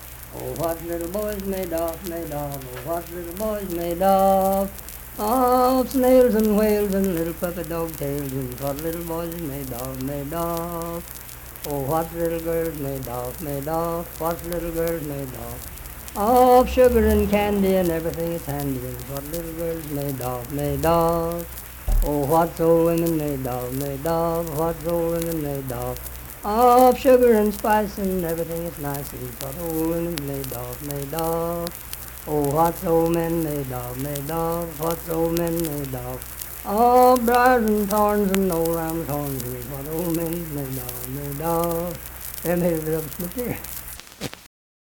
Unaccompanied vocal music performance
Verse-refrain 4(4w/R).
Children's Songs, Dance, Game, and Party Songs
Voice (sung)